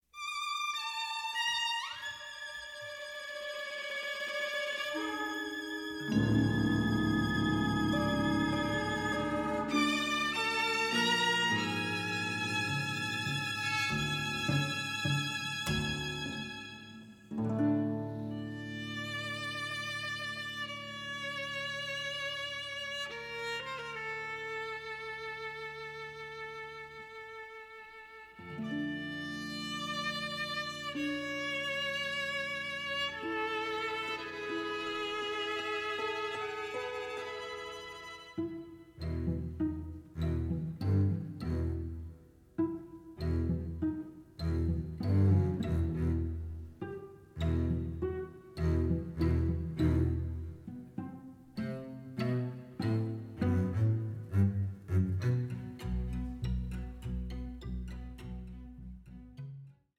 conducts a large orchestra recorded in Los Angeles.